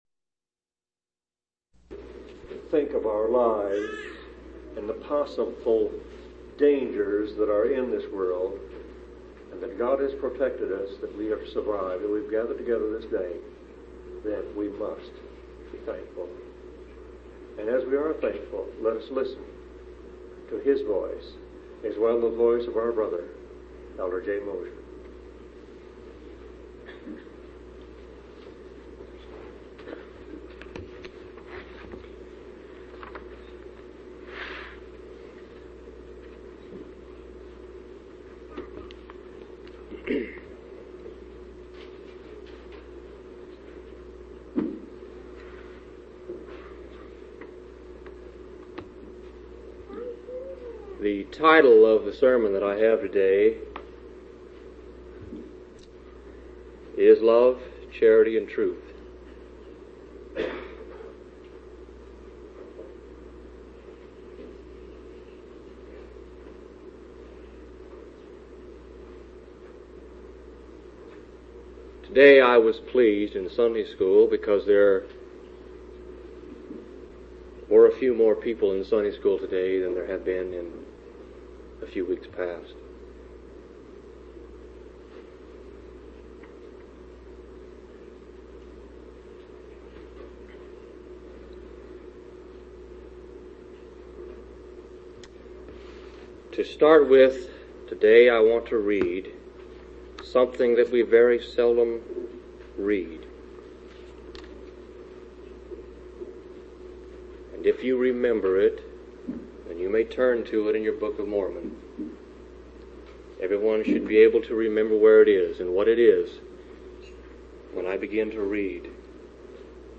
9/25/1983 Location: Phoenix Local Event